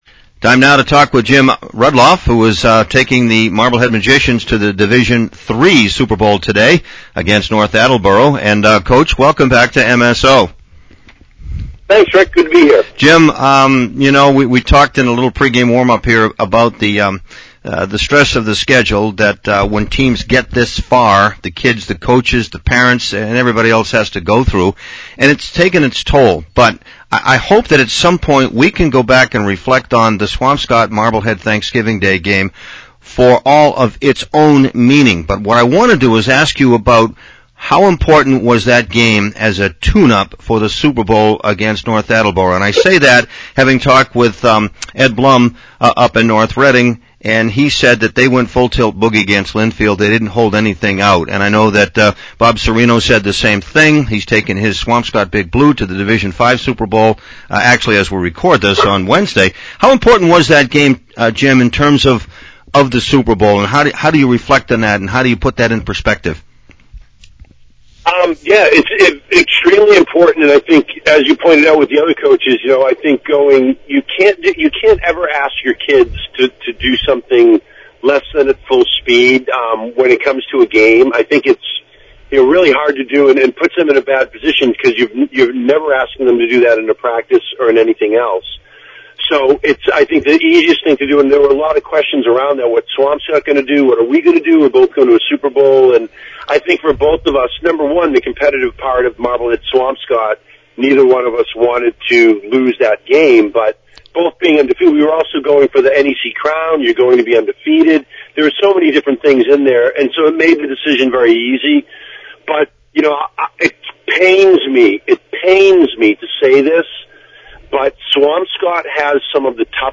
(Audio) Post-game, Pre-game